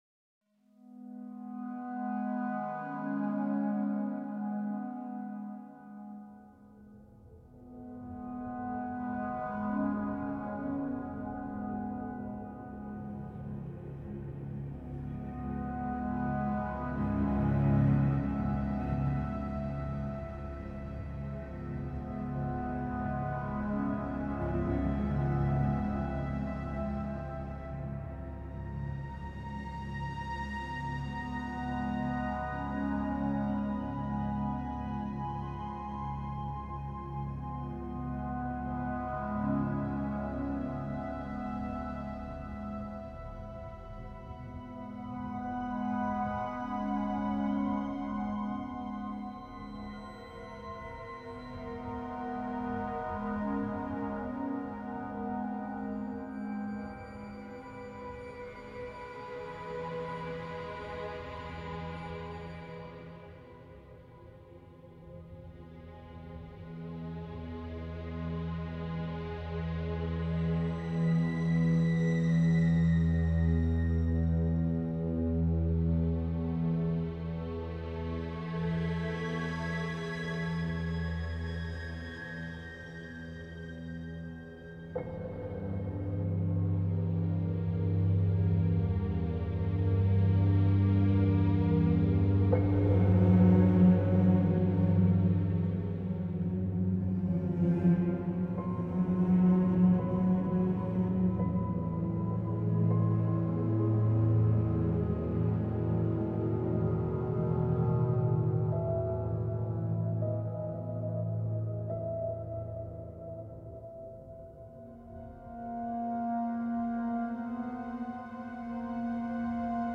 Ambience 4 - I'm not Alone.wav